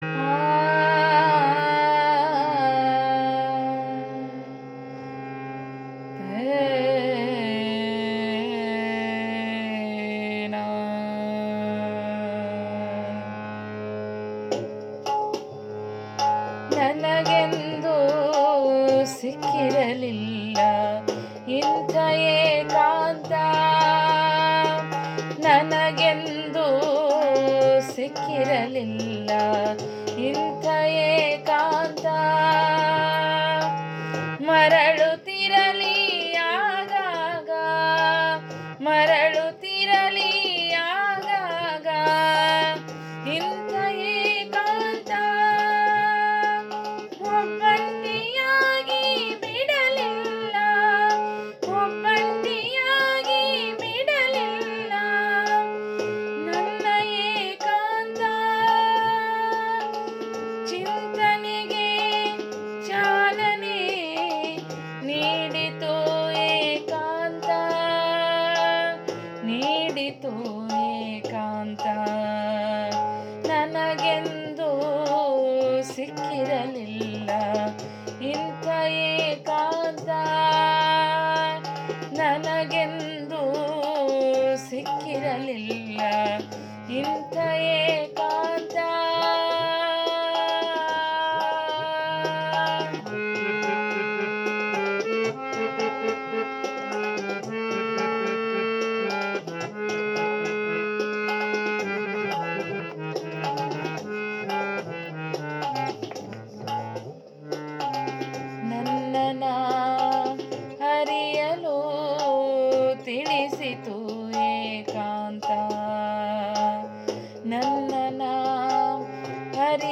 ರಾಗ ಸಂಯೋಜಿಸಿ ಸುಶ್ರಾವ್ಯವಾಗಿ ಹಾಡಿದ್ದಾರೆ